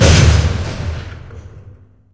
skylift_stop.ogg